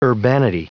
Prononciation du mot urbanity en anglais (fichier audio)
Prononciation du mot : urbanity